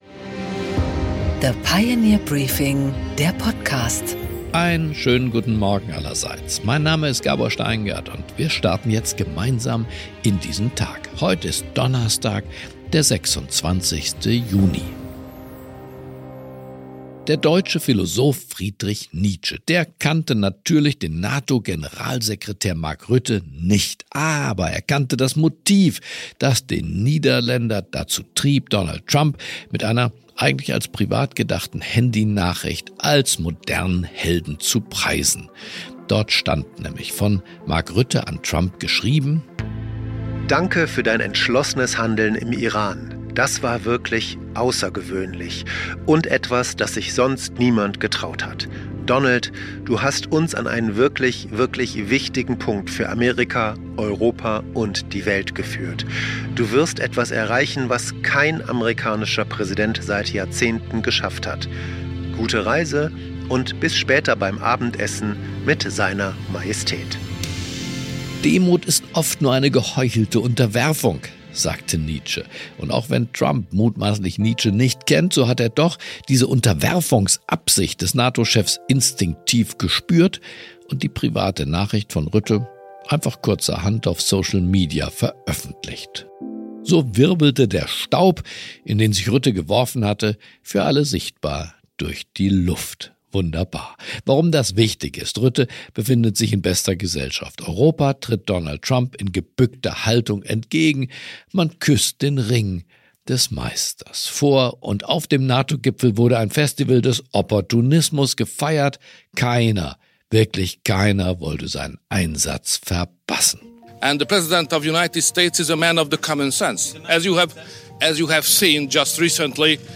Im Interview: Gabor Steingart spricht mit Eric Gujer, dem Chefredakteur der Neuen Zürcher Zeitung über den Nahen Osten – und welche Rolle Donald Trump und Europa dort spielen.